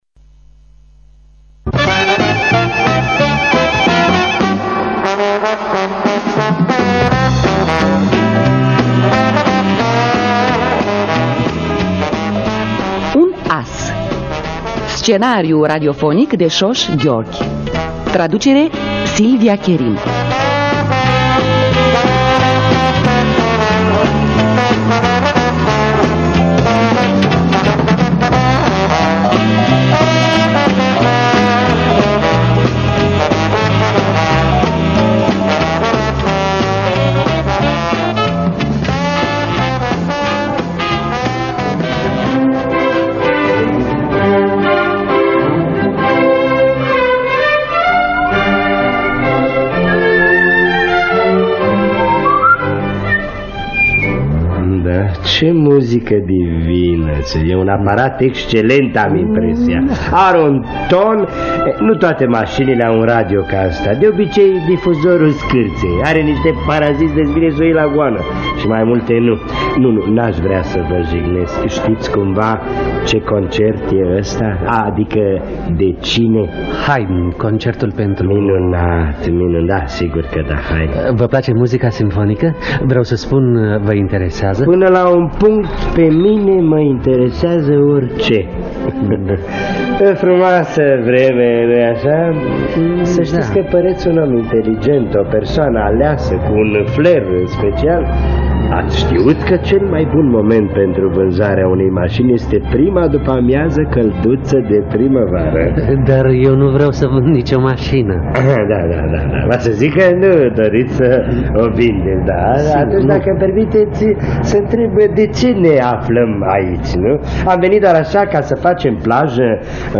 Un as de György Sós – Teatru Radiofonic Online